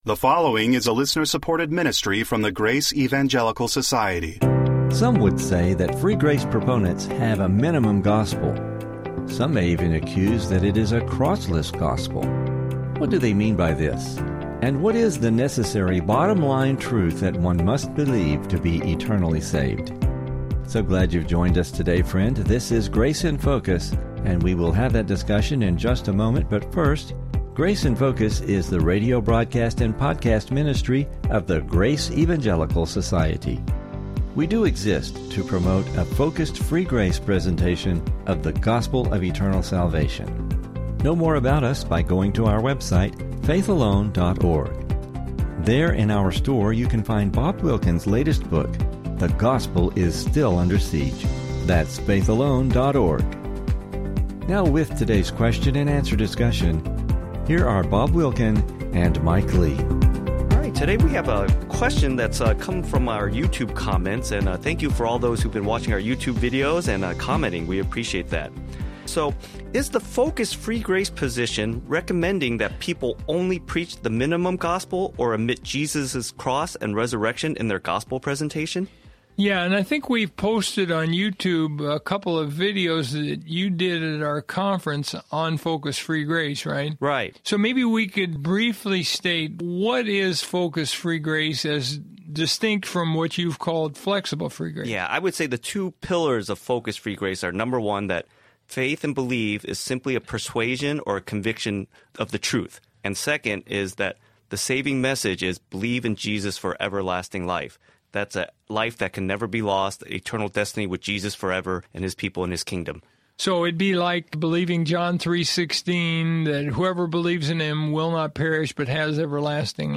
What is the necessary, bottom-line truth that one must believe to be eternally saved? Please listen for an interesting discussion and clarification on this topic.